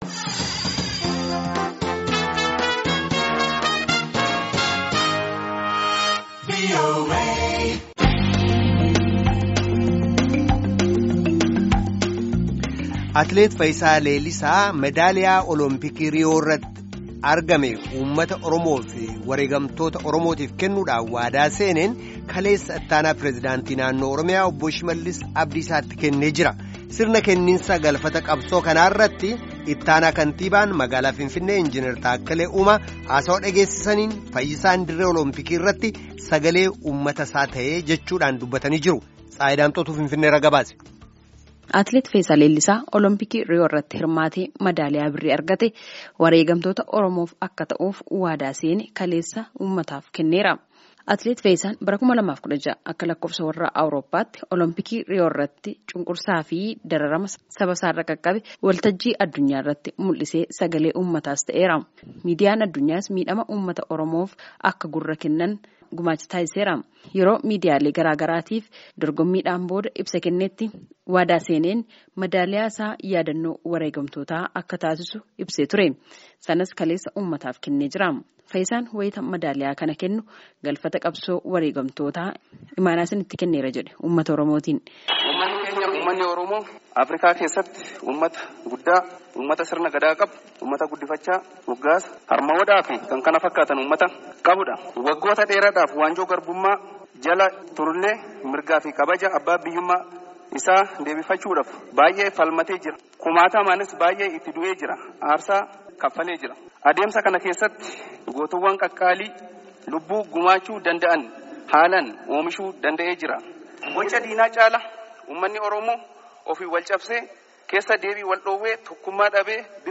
Gabaasaa guutuu caqasaa.